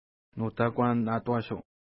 Pronunciation: nu:ta:kwa:n-na:twa:ʃu: